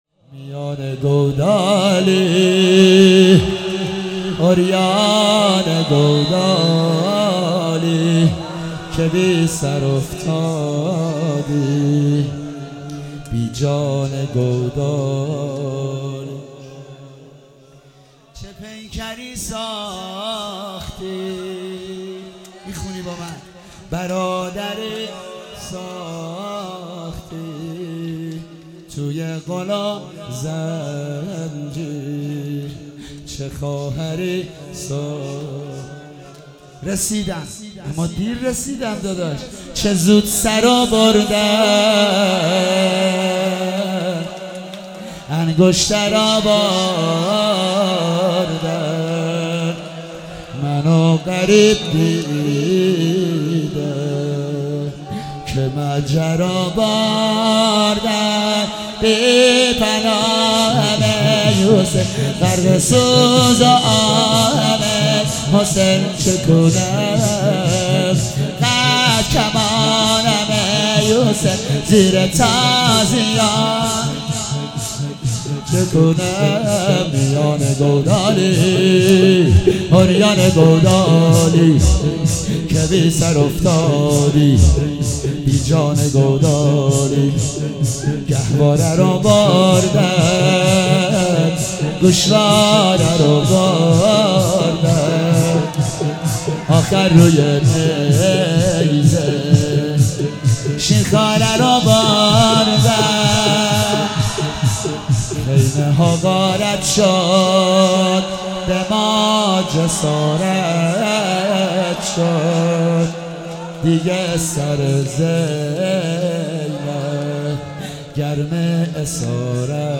شب دوم محرم96 - شور - میانه گودالی